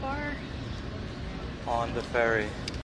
描述：用DS40录制的来自梅角刘易斯渡轮右舷甲板的空栏，并在Wavosaur中进行编辑。
Tag: 斗篷可 - 刘易斯 - 轮渡 特拉华州 现场记录 新球衣 海洋